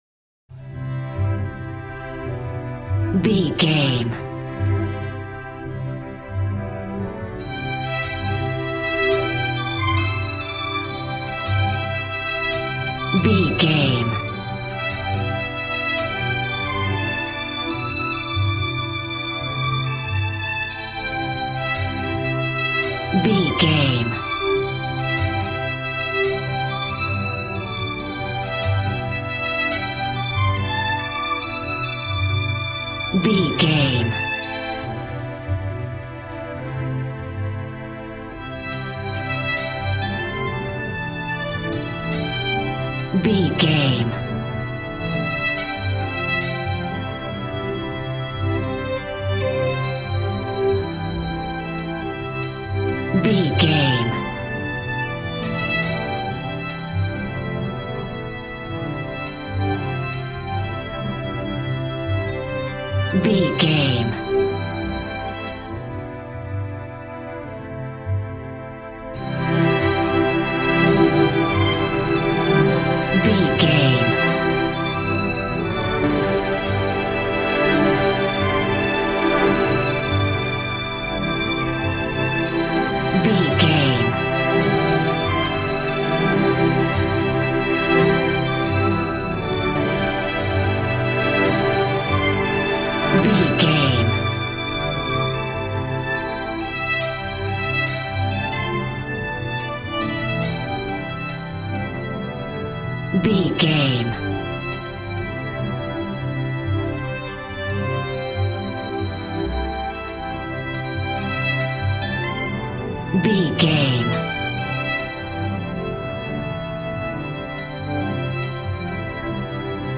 Ionian/Major
joyful
conga
80s